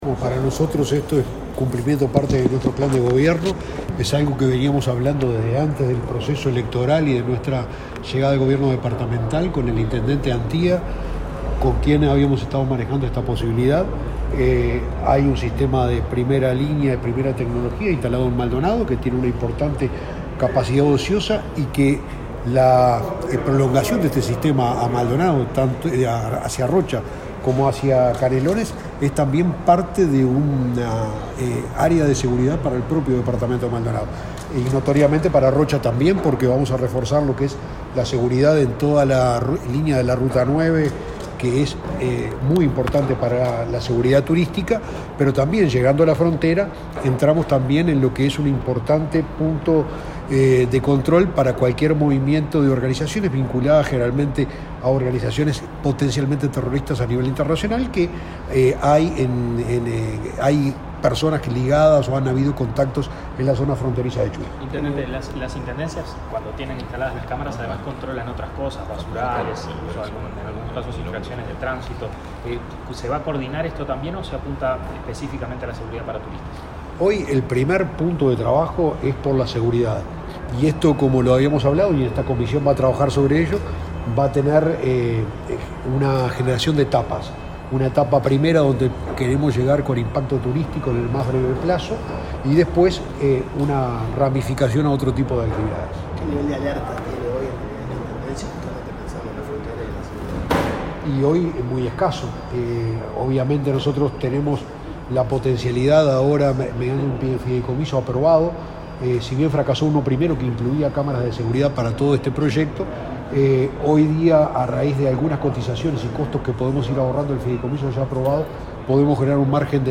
Declaraciones a la prensa de los intendentes de Rocha, y de Maldonado, Alejo Umpiérrez y Enrique Antía
El secretario de la Presidencia, Álvaro Delgado, se reunió, este 20 de julio, con autoridades del Ministerio del Interior e intendentes de Maldonado, Rocha y Canelones, para la firma del convenio que establece la expansión del Centro de Videovigilancia de Maldonado a esos departamentos. Tras el evento, los intendentes Enrique Antía y Alejo Umpiérrez efectuaron declaraciones a la prensa.